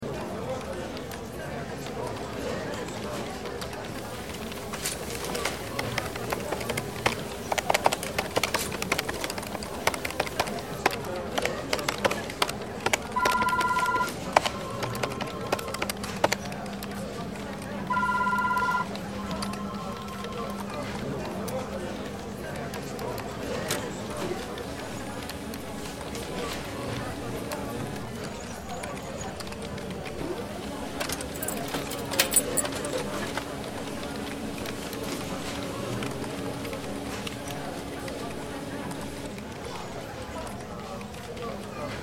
دانلود آهنگ فضای دفتر از افکت صوتی طبیعت و محیط
دانلود صدای فضای دفتر از ساعد نیوز با لینک مستقیم و کیفیت بالا
جلوه های صوتی